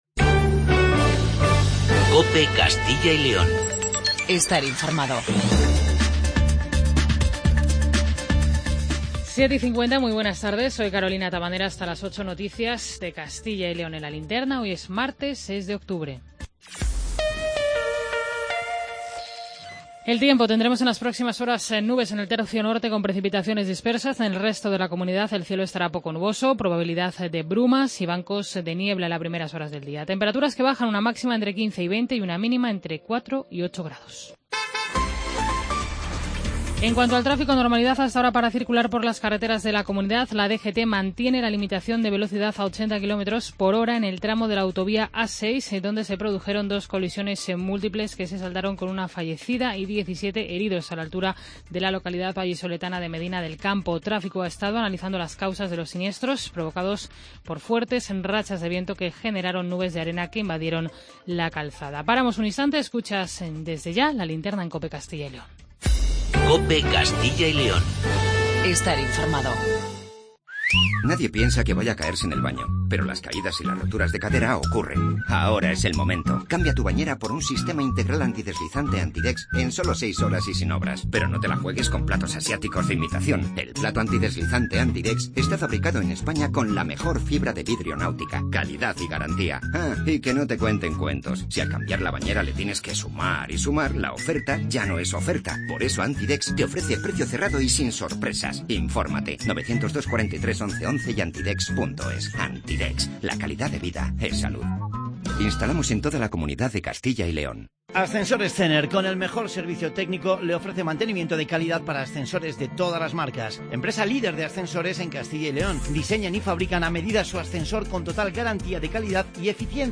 AUDIO: Informativo regional